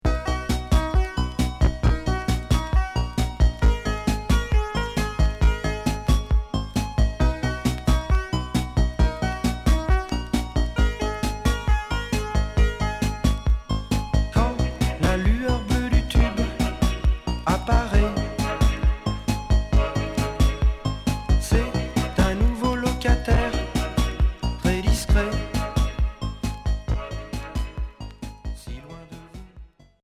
Minimal synth Premier 45t retour à l'accueil